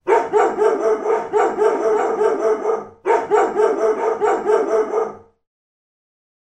dog-dataset
dogs_0015.wav